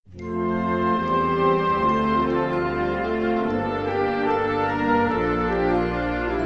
Gattung: Moderner Einzeltitel
A4 Besetzung: Blasorchester Zu hören auf